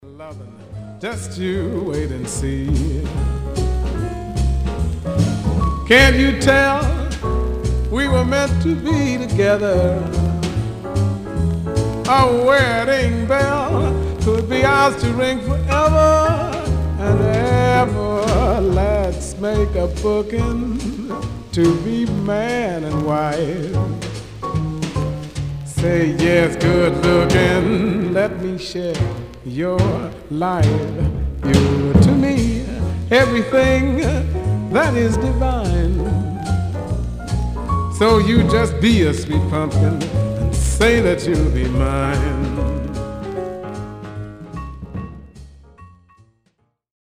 Stereo/mono Mono
Jazz (Also Contains Latin Jazz)